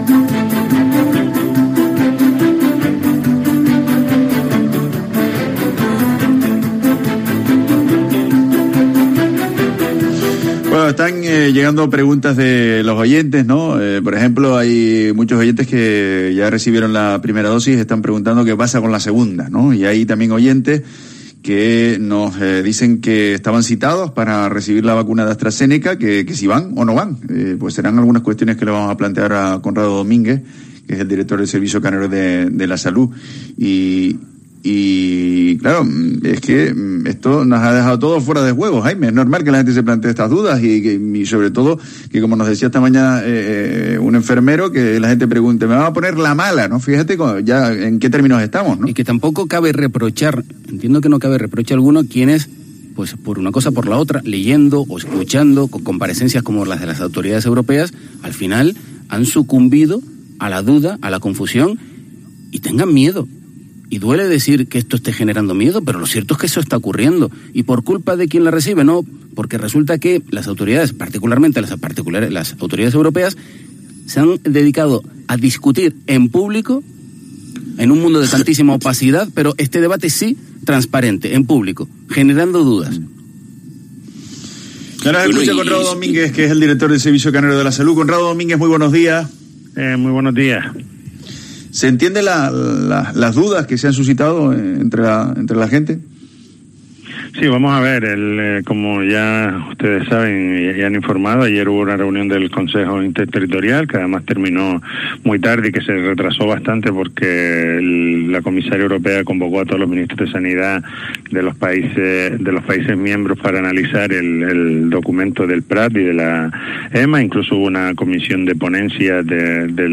Conrado Domínguez, director del Servicio Canario de la Salud